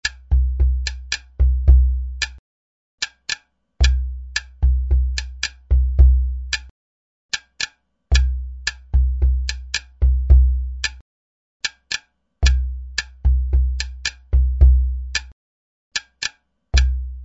3 drum sections, 3 stick & palm sections.